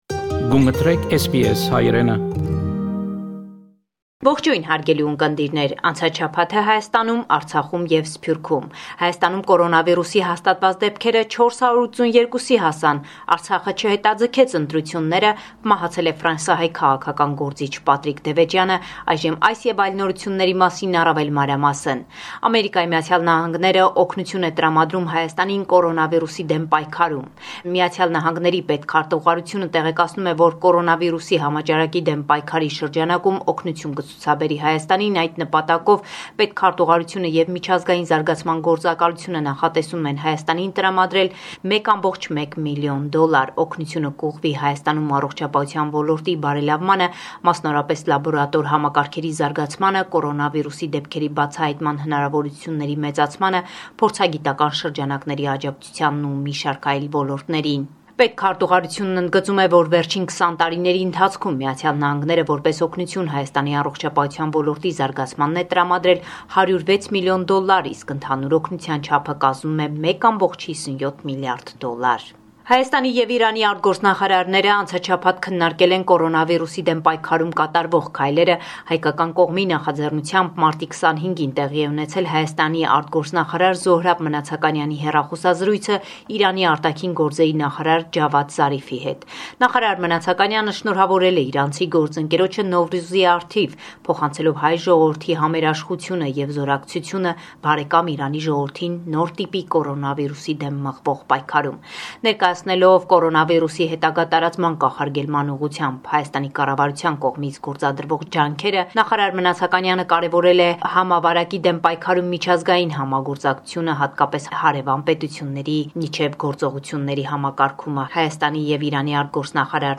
News from Armenia, Artsakh and the Diaspora